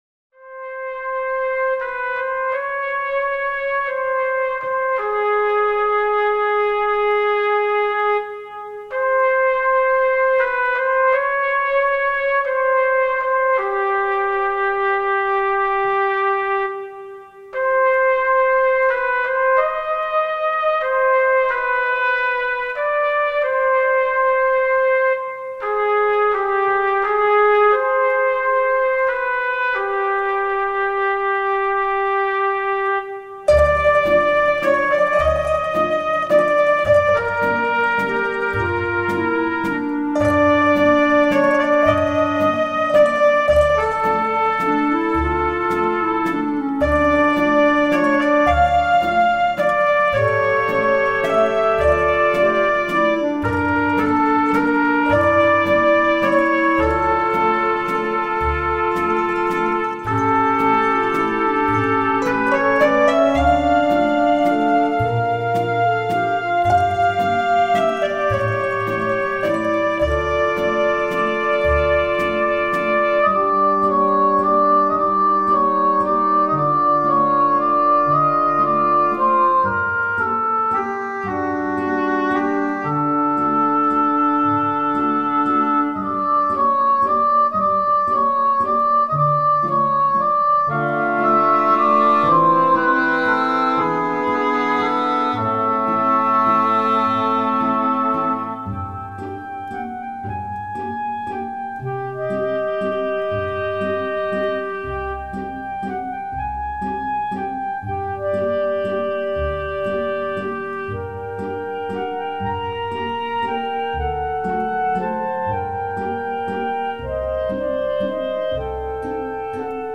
Soundtrack, Classical, Score